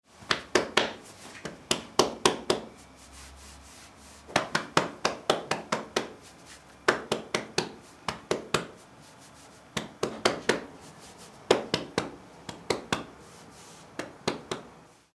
Dar palmadas a alguien en la espalda
palmada
Sonidos: Acciones humanas